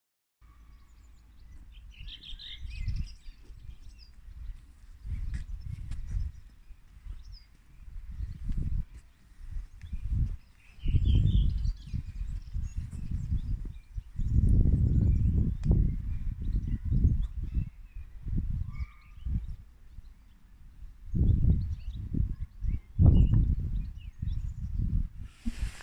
Kven syng her?
bogstad_gaard_trast.m4a